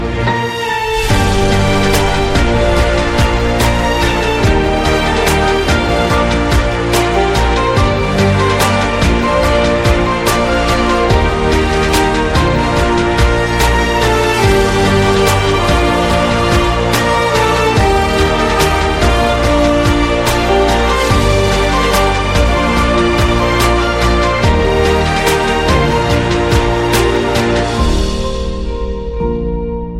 Emotional Melody For Your Phone